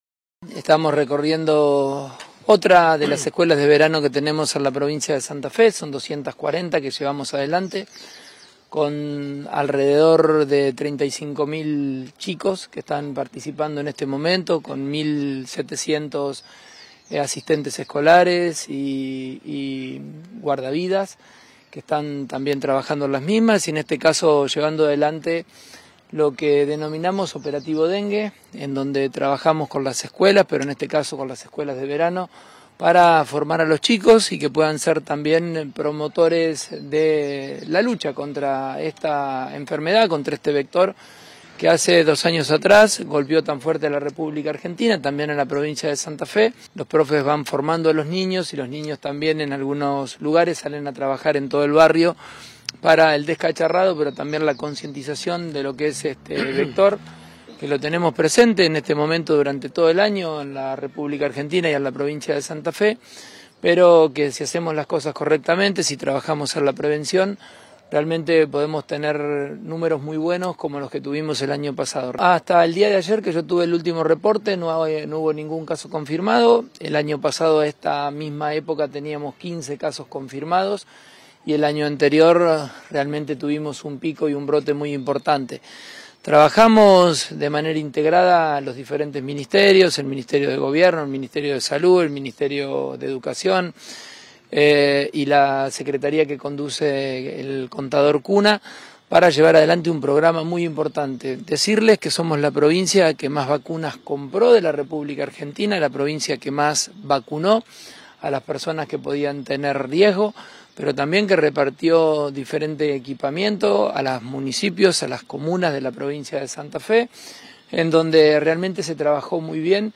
El gobernador lo afirmó este martes por la mañana, al recorrer la Escuela de Verano que funciona en el CEF Nº 29 de la ciudad de Santa Fe.